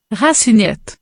Im frankophonen Teil Kanadas wird es als Racinette [ˈʁa.si.nɛt] (